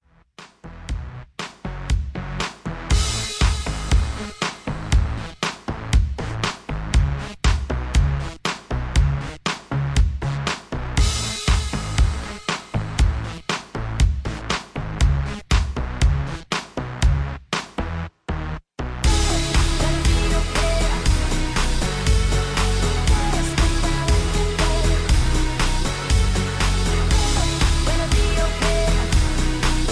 Key-C Sharp) Karaoke MP3 Backing Tracks